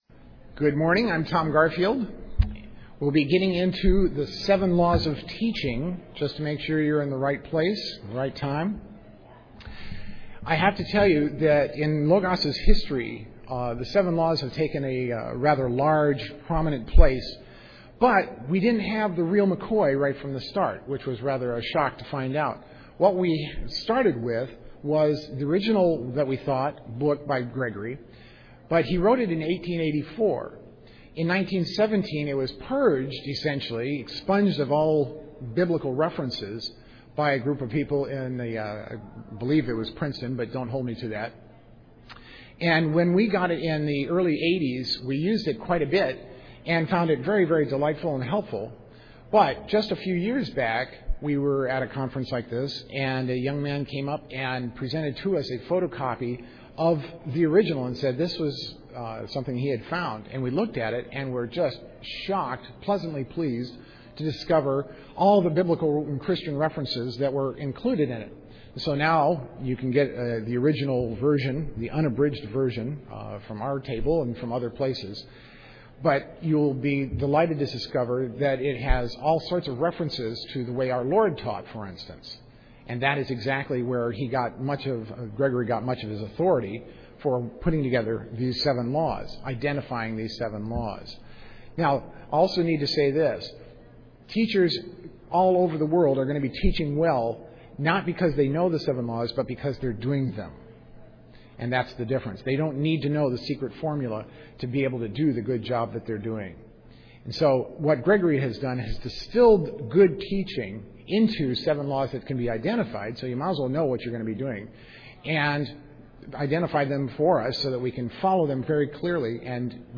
2007 Workshop Talk | 0:44:24 | All Grade Levels, General Classroom